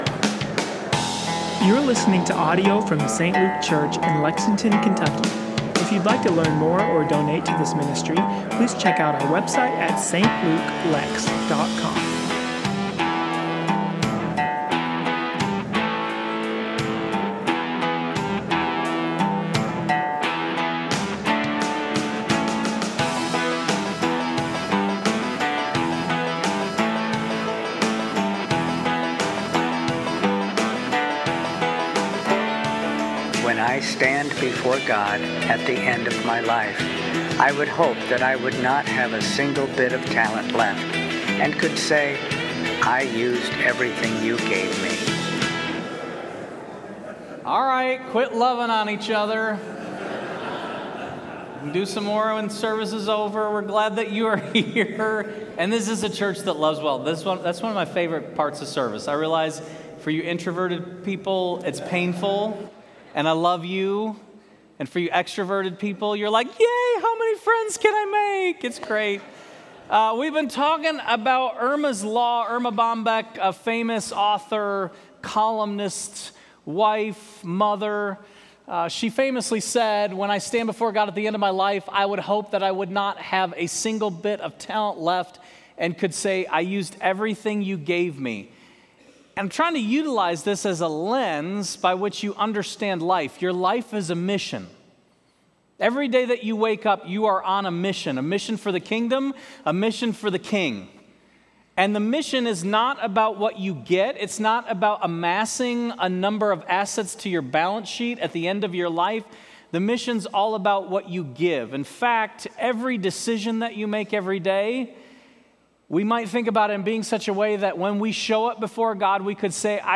Sermon Title: Generous with Our Finances- Erma’s Law: Arriving Home with Nothing Left